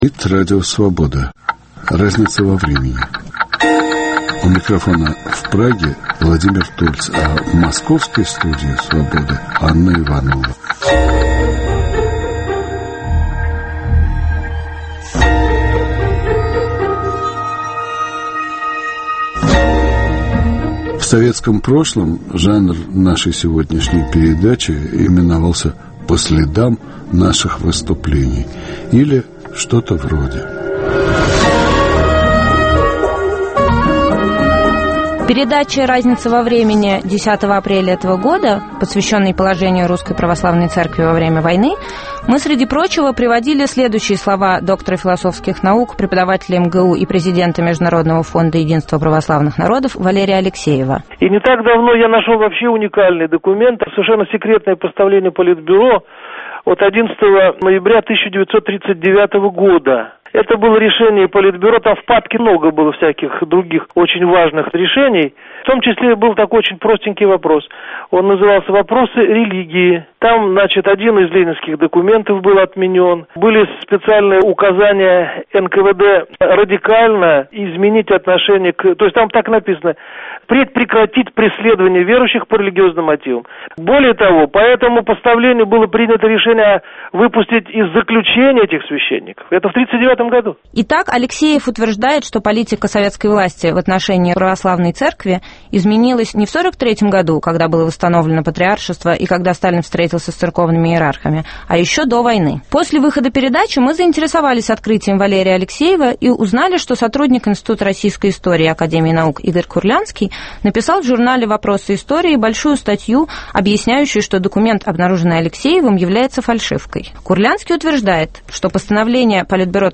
В программе "Разница во времени" дискуссия "Подлинное или подлог?